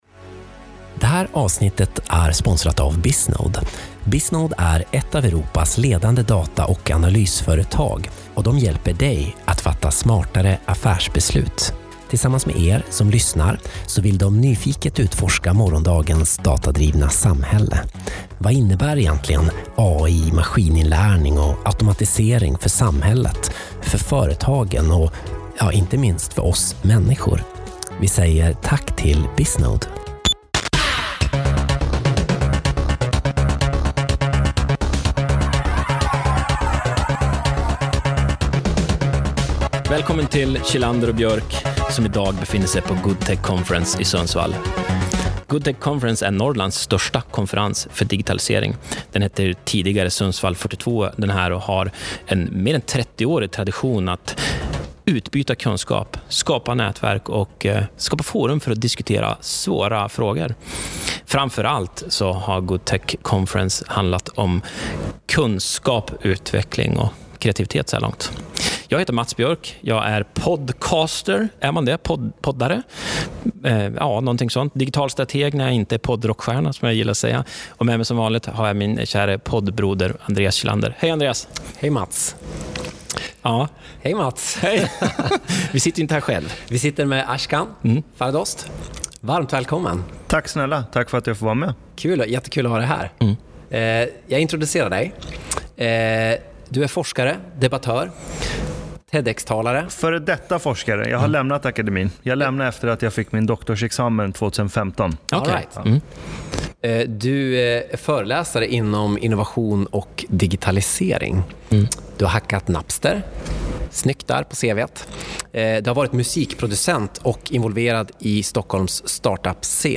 Ett fartfyllt samtal om passionen vi delar… framtiden!
Vi pratar med honom minuterna innan äntrar scen för att lysa upp den norrländska himlen med analogier, energi och kunskap.